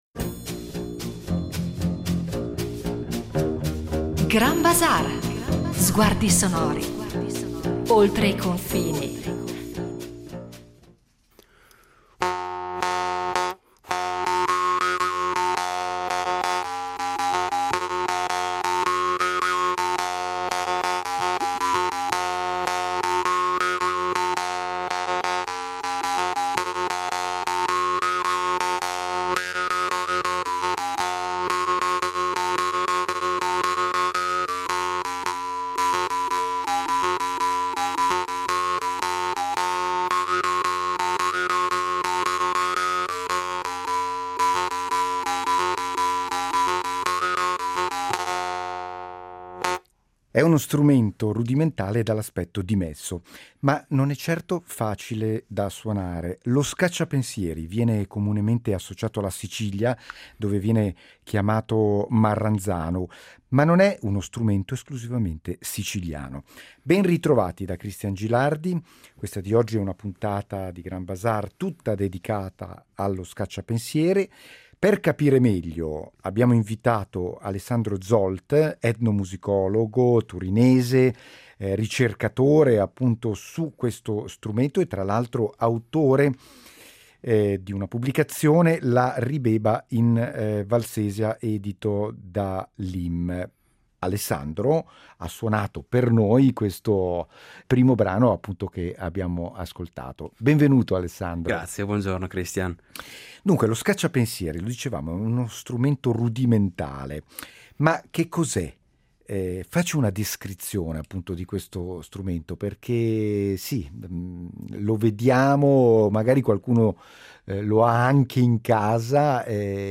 Tuttavia, disponiamo di informazioni sufficienti per comprendere uno strumento fabbricato e suonato in tutto il mondo, costruito da artigiani o prodotto in serie in numerose forme che riflettono il materiale a disposizione dei costruttori, e di origine antica. Grand Bazaar in due puntate cercherà di tracciare la storia di questo antico strumento assieme all’etnomusicologo